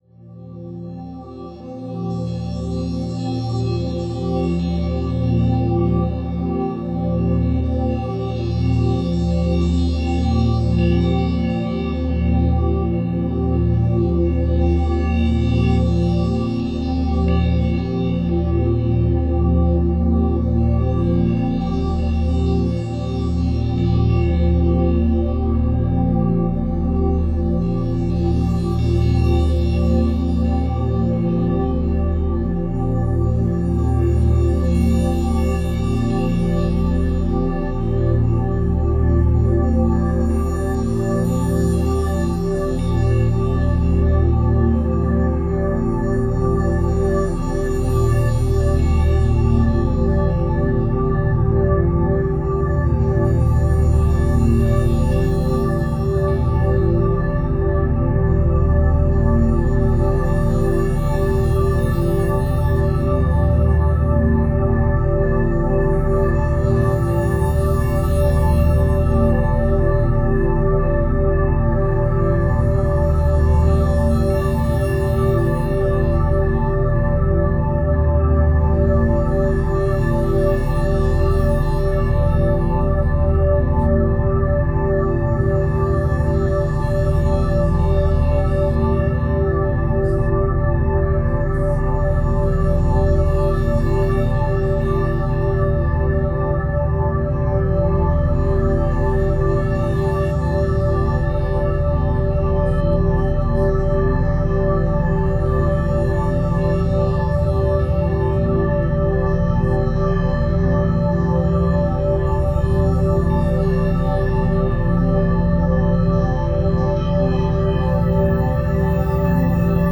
Meditation
Meditation.mp3